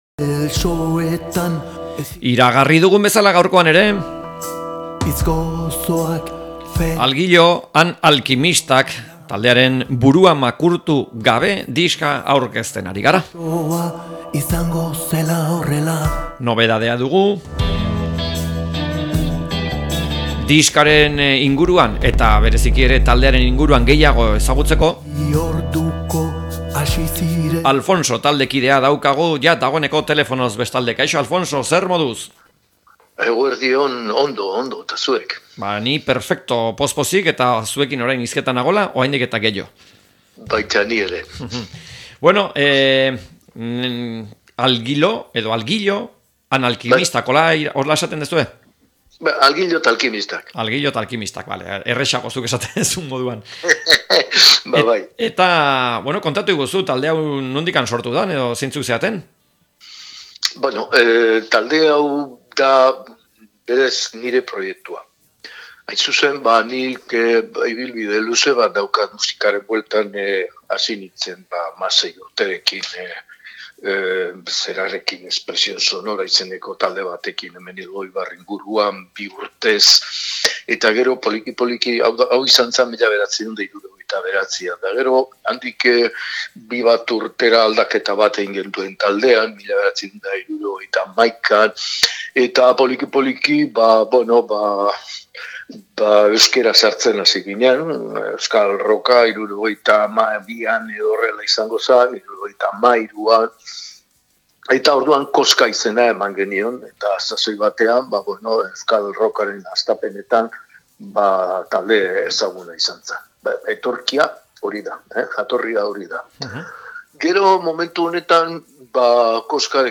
Alguillo & Alkimistak taldeari elkarrizketa
Elkarrizketak